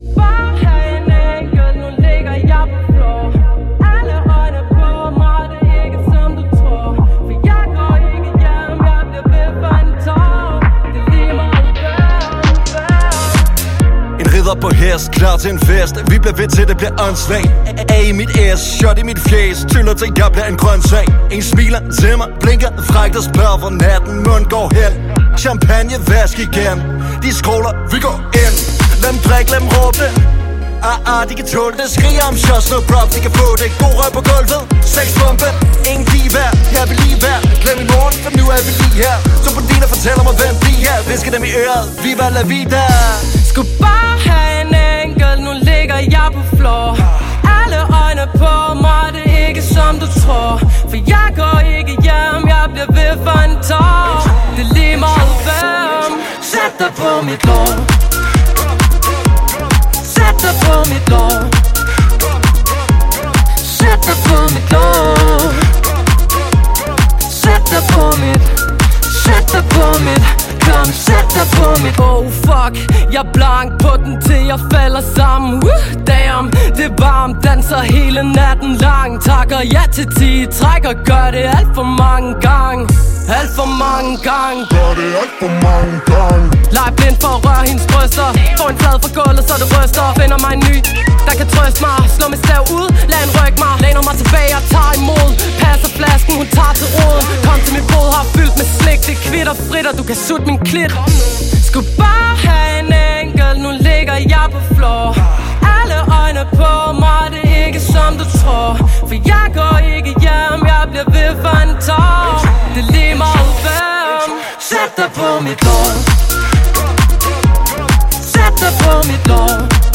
• Hip hop
• Pop
Duo
(uden musikere)